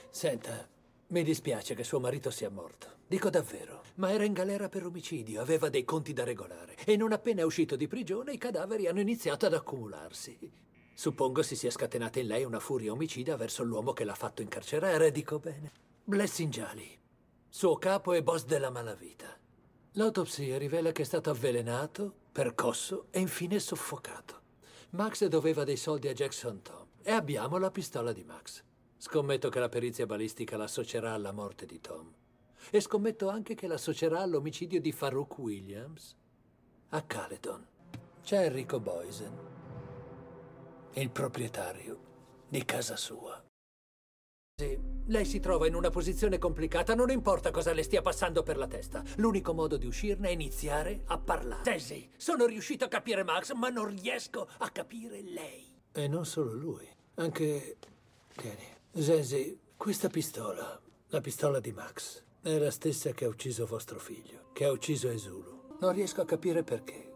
nel telefilm "Unseen", in cui doppia Waldemar Schutz.